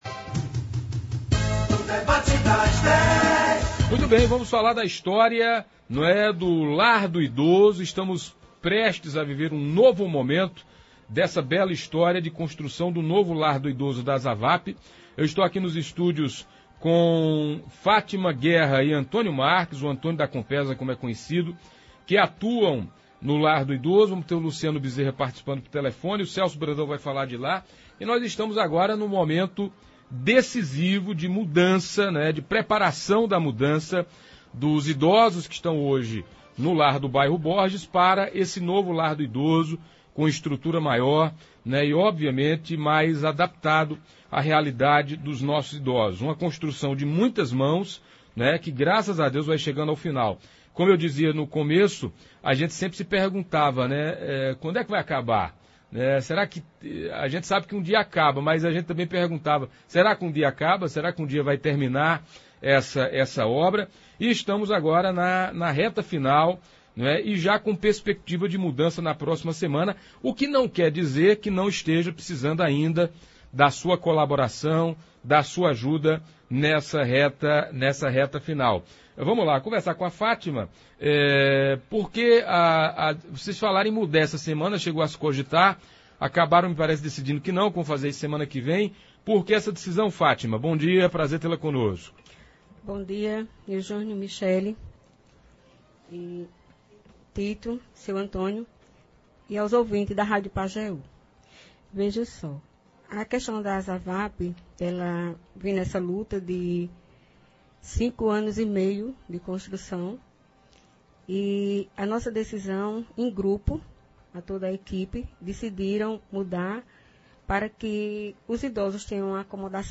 Ouça abaixo na íntegra como foi o debate de hoje: